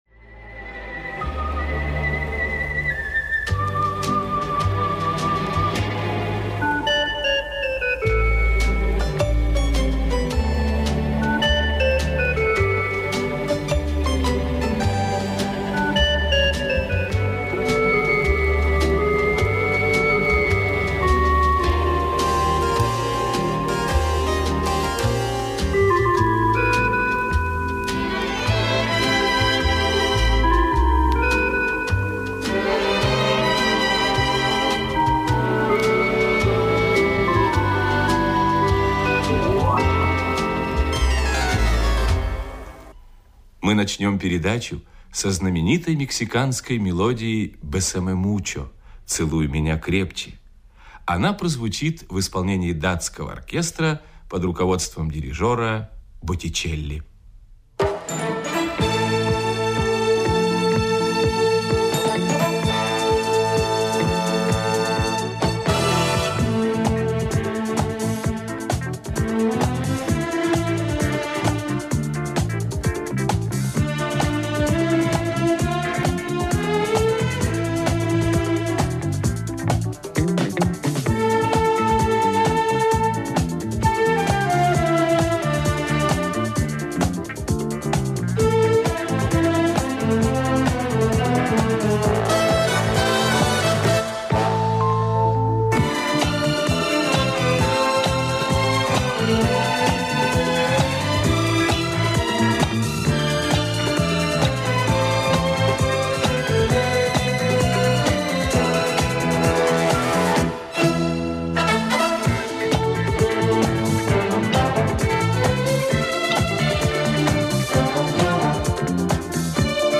самба
инструментал